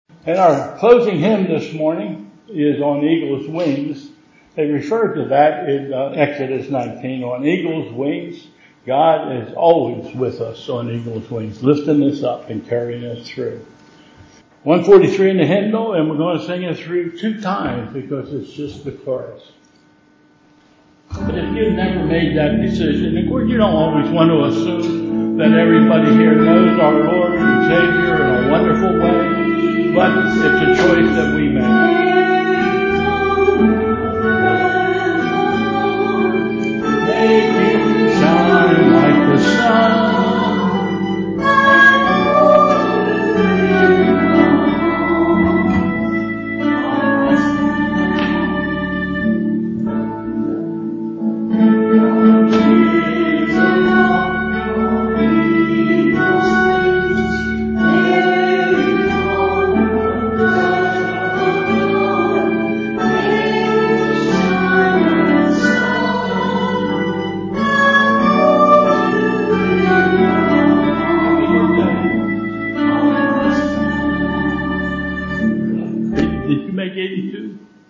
Bethel Church Service